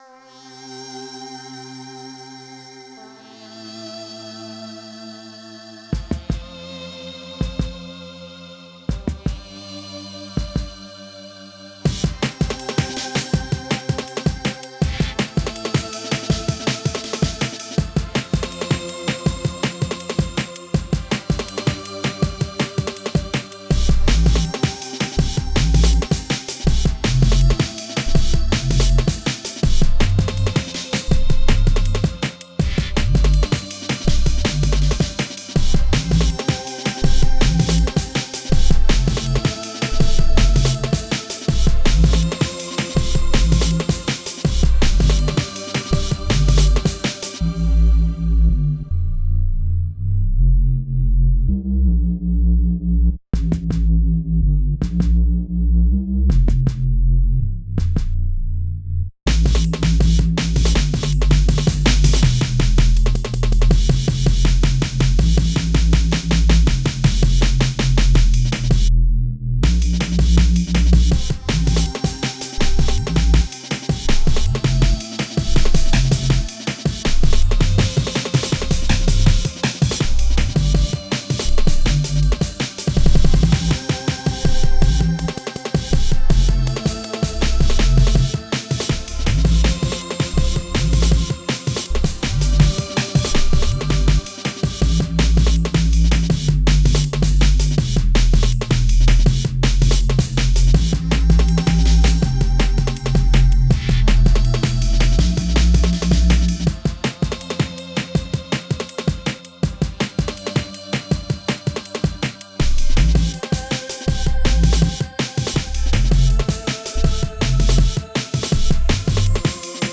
ambient, breakcore,